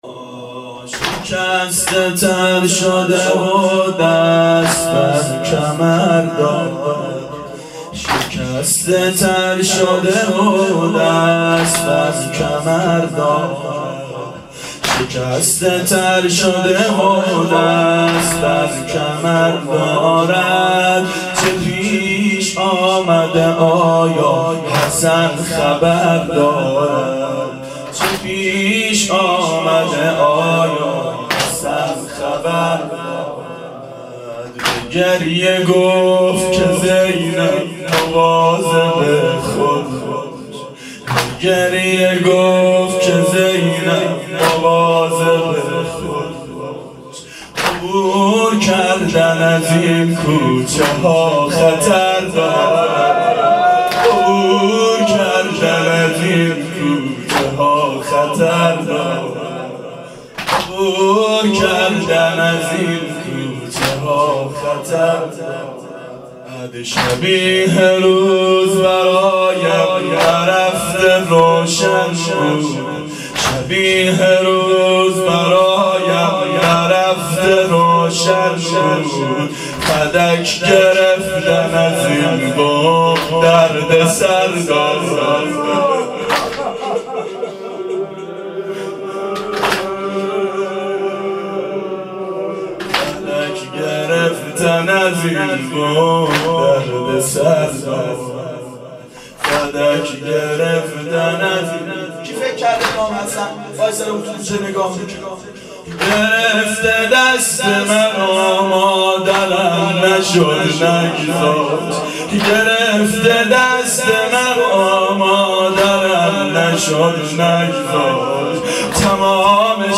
• شب شهادت حضرت زهرا سلام الله علیها 1389 هیئت عاشقان اباالفضل علیه السلام